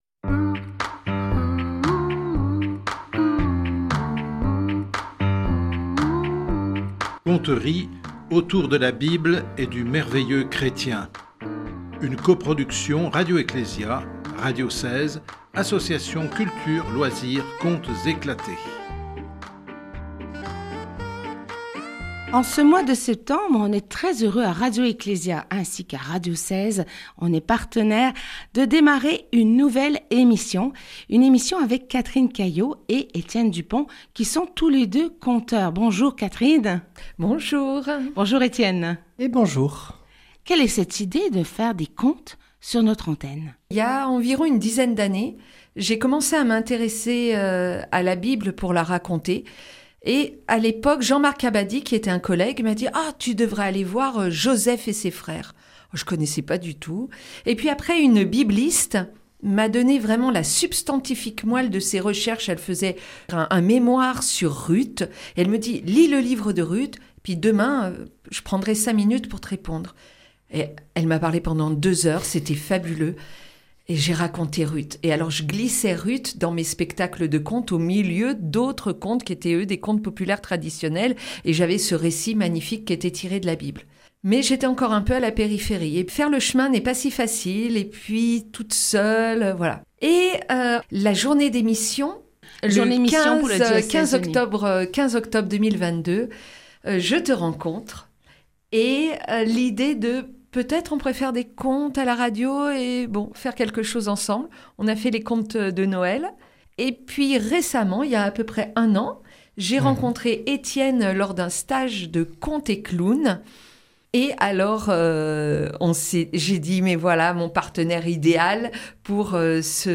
Tout en restant fidèle à l'esprit du texte, j'en fais des interprétations libres adaptées à la forme orale.